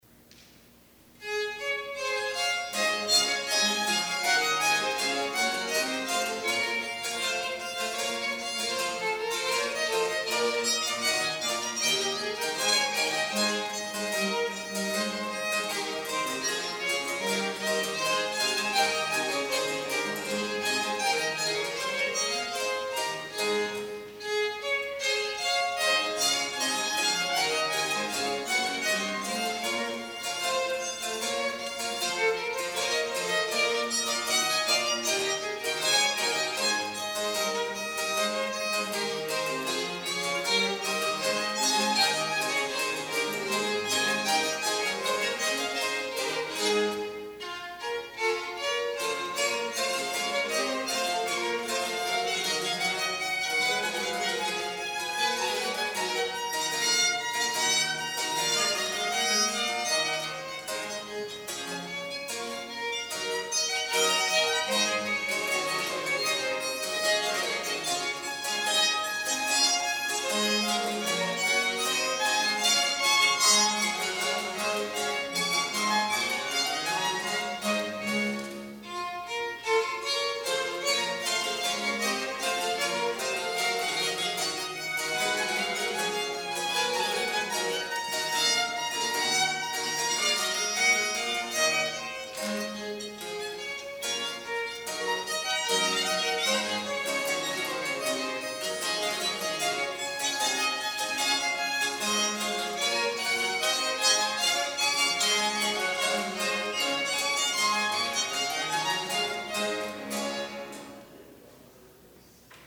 Performers:  Jefferson Baroque